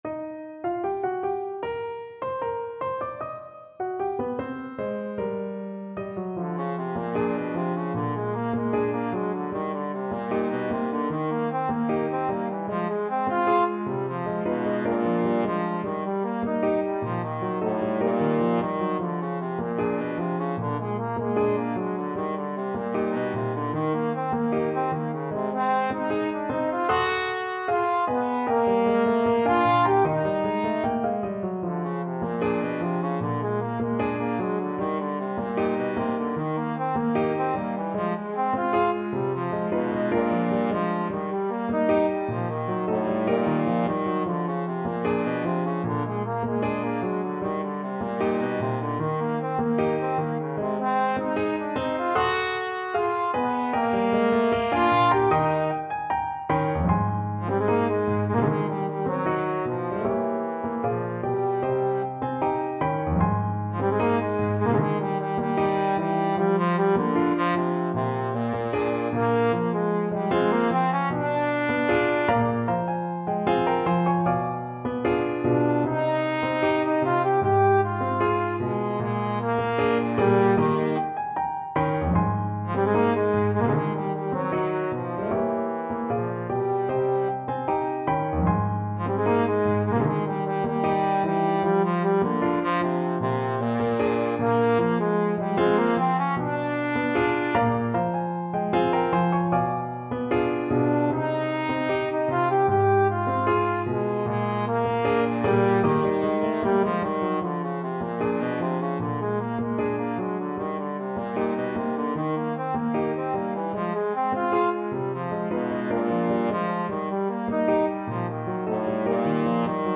Trombone
Eb major (Sounding Pitch) (View more Eb major Music for Trombone )
2/4 (View more 2/4 Music)
Very slow march time = 76 Very slow march time
G3-G5
Jazz (View more Jazz Trombone Music)
joplin_mexican_serenade_TBNE.mp3